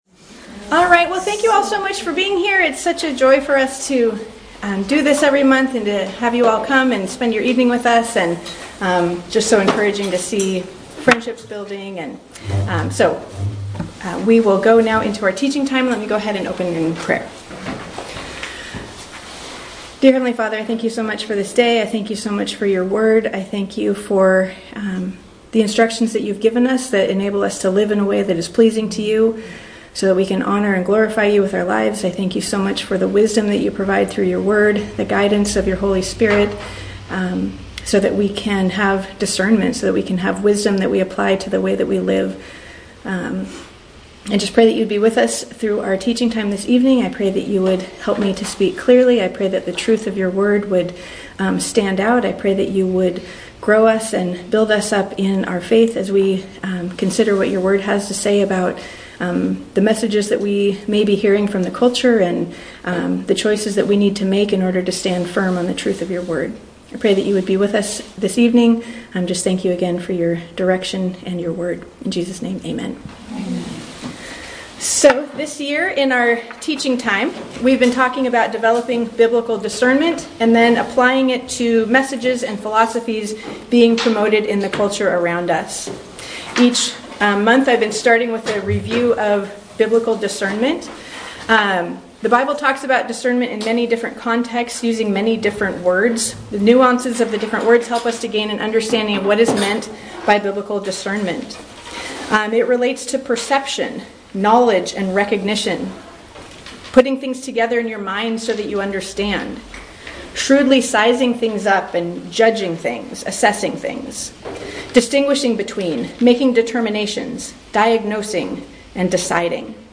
Women’s Fellowship Night – April 2026